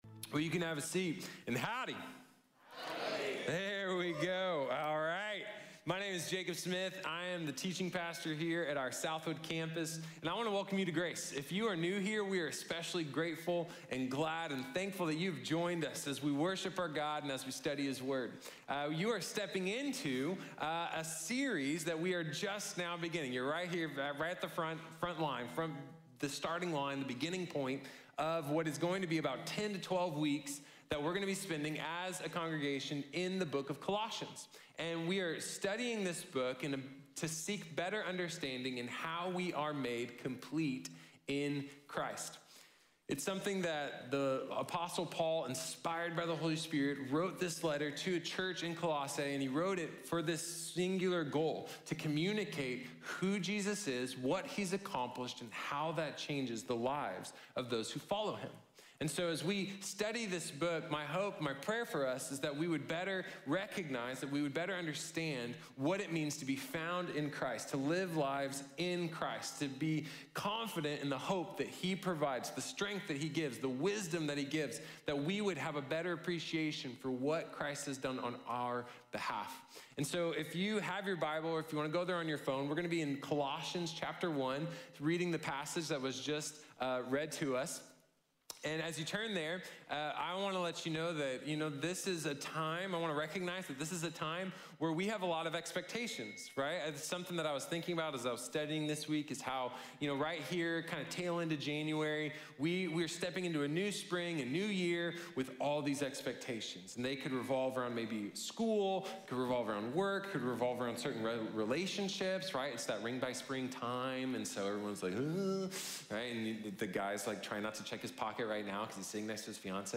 Becoming Complete | Sermon | Grace Bible Church